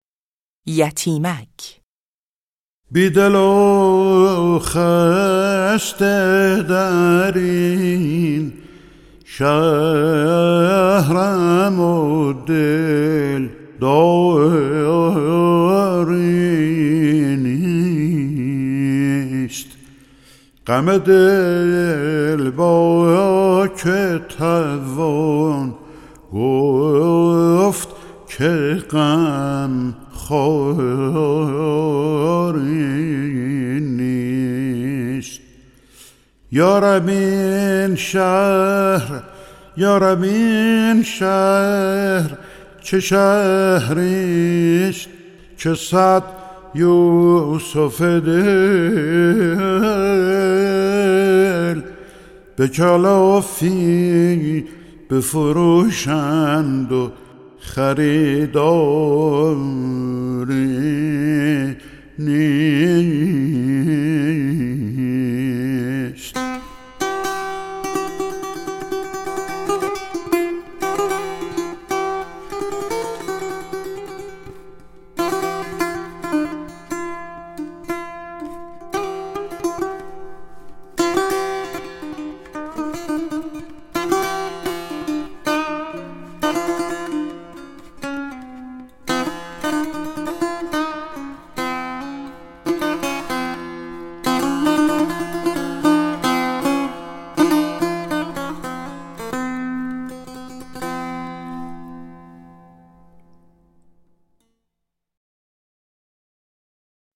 مرحوم استاد حسن کسایی نوازنده نامی «نی» در سالهای پایانی عمر خود (86- 1385) دوبار ردیف موسیقی ایرانی را از ابتدا تا انتها روایت کرد: یک بار با سه تار و بار دیگر با نی.
گوشه یتیمک یکی از قالب هایی است که در آوازهای دیگر از جمله بیات ترک(زند) و افشاری نیز استفاده می شود و از لحاظ تقسیم بندی عروضی جزء گوشه هایی است که دارای ریتم ثابت و مشخصی است.